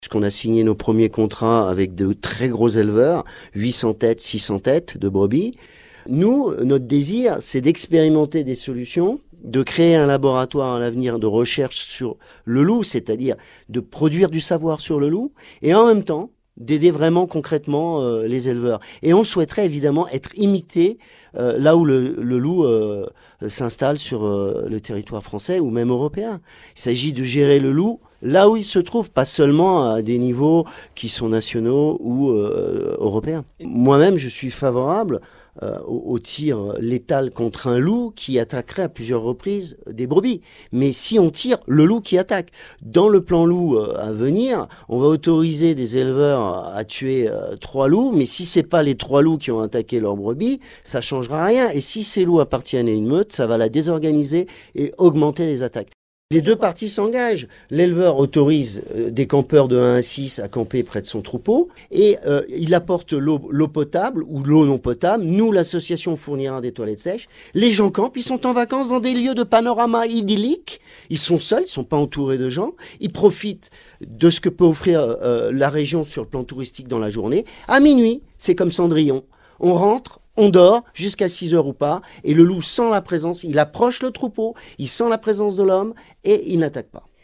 • Des campeurs contre le loup. Interview